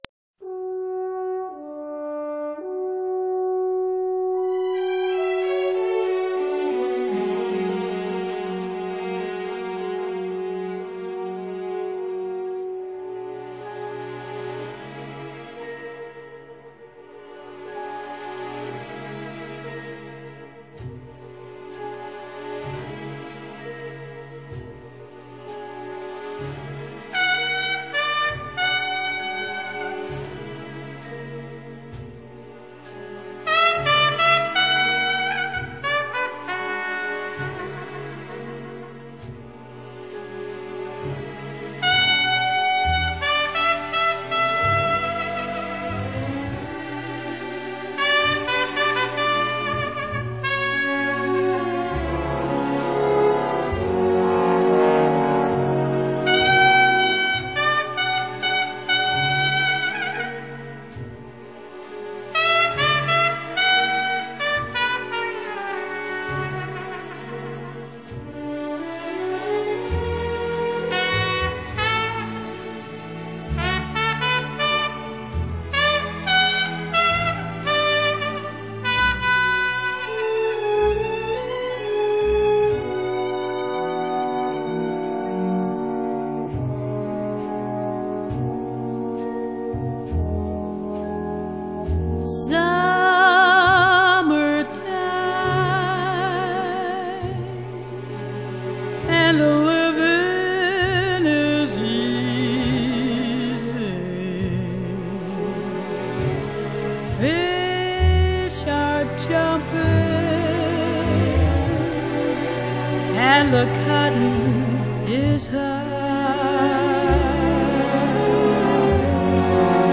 Orchestra & Chorus